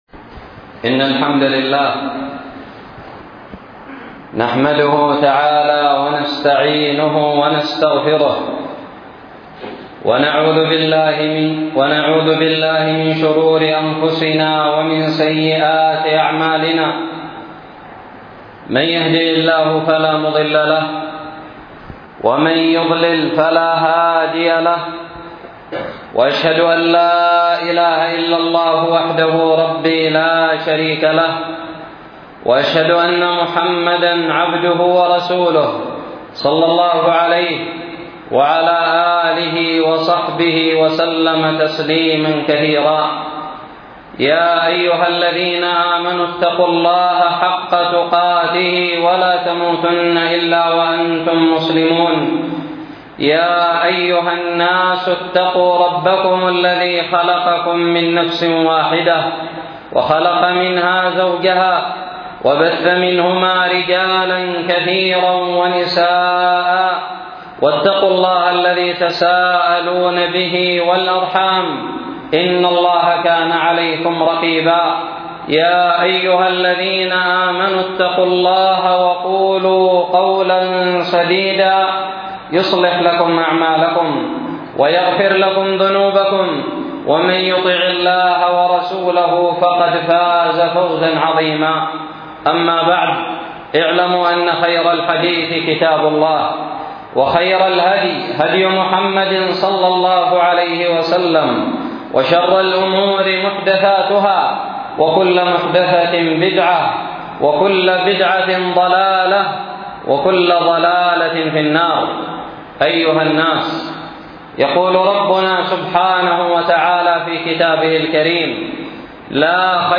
خطب الجمعة
ألقيت بدار الحديث السلفية للعلوم الشرعية بالضالع في 12 رمضان 1437هــ